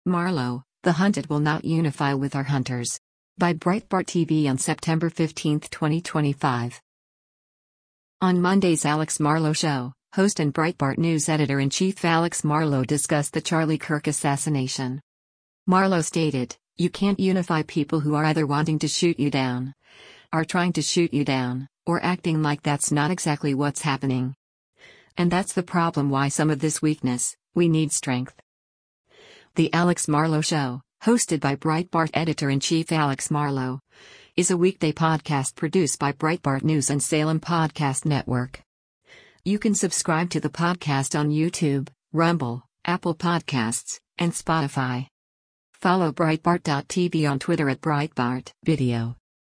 On Monday’s “Alex Marlow Show,” host and Breitbart News Editor-in-Chief Alex Marlow discussed the Charlie Kirk assassination.